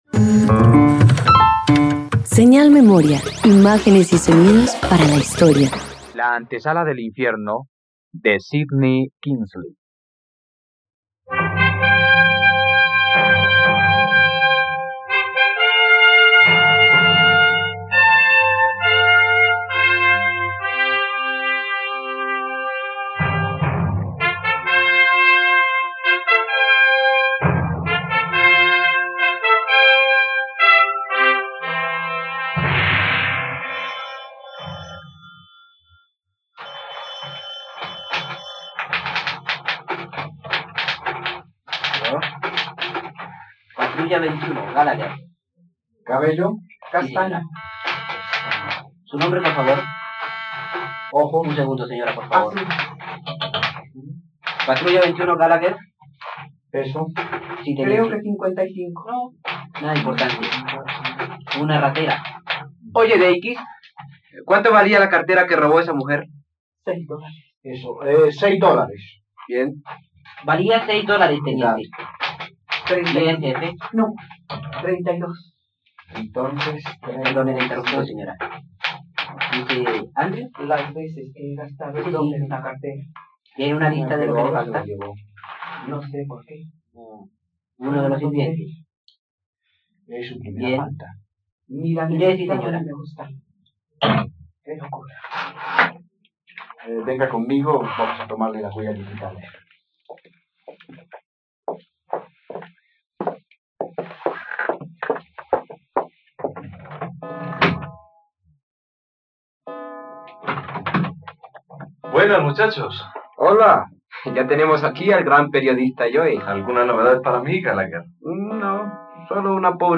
La antesala del infierno - Radioteatro dominical | RTVCPlay
..Conoce la adaptación de “La antesala del infierno" para radio.